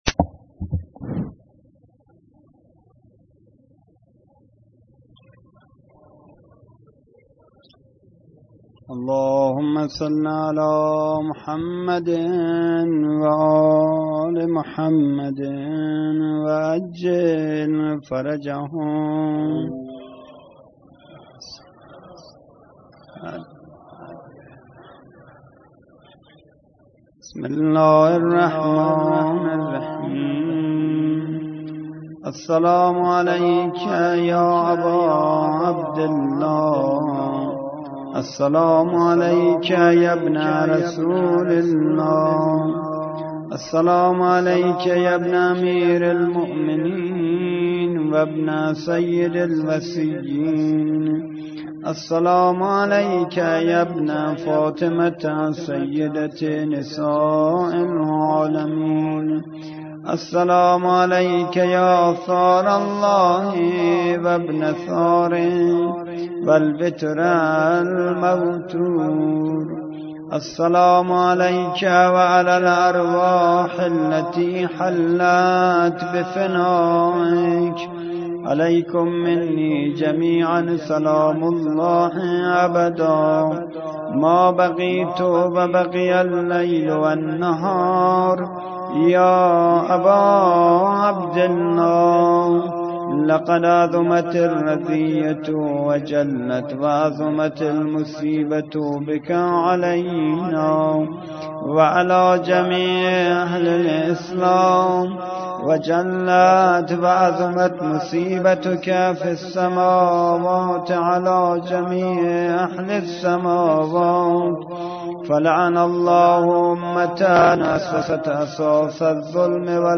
خیمه گاه - هیئت مهدیه احمد آباد - زیارت عاشورا